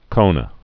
(kōnə)